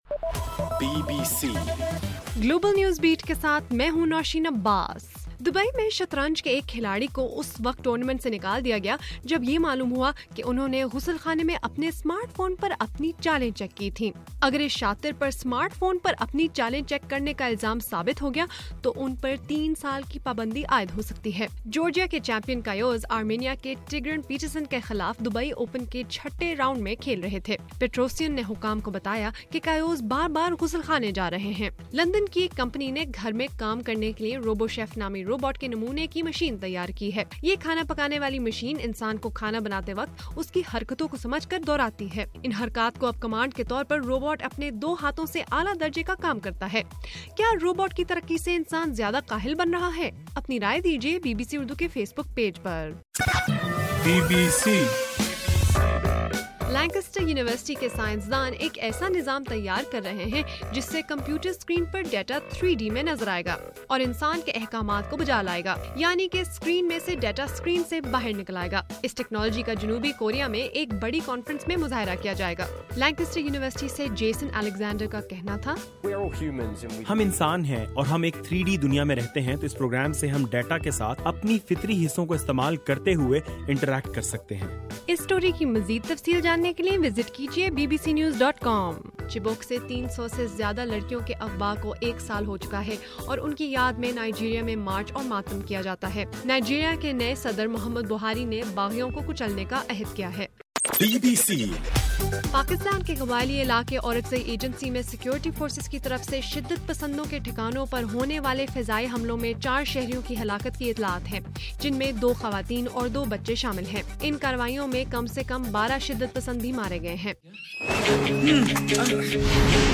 اپریل 14: رات 9 بجے کا گلوبل نیوز بیٹ بُلیٹن